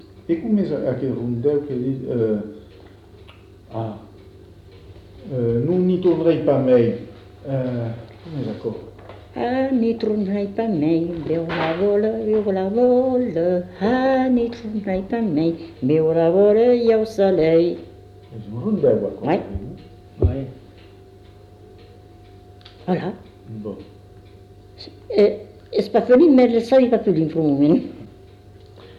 Lieu : Cazalis
Genre : chant
Effectif : 1
Type de voix : voix de femme
Production du son : chanté
Danse : rondeau